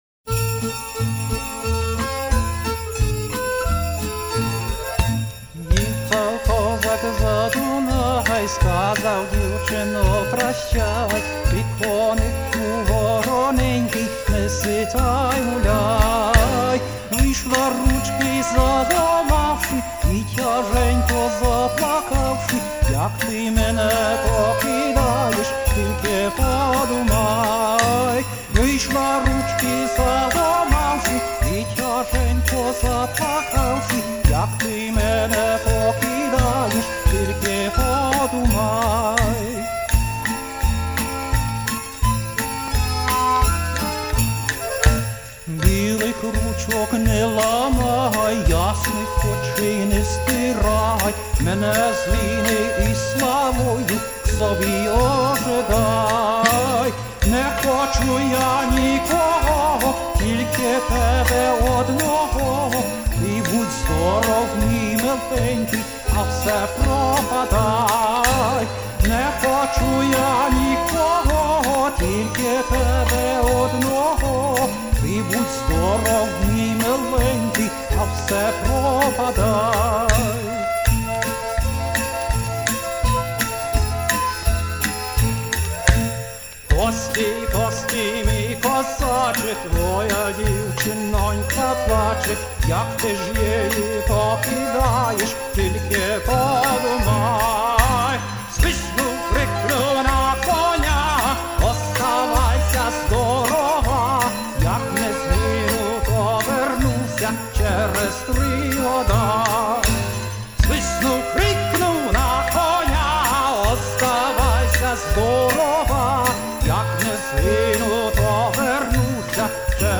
Может у кого нибудь есть украинская песня "йихав козак за Дунай"
Iван Козловський - Йихав козак за дунай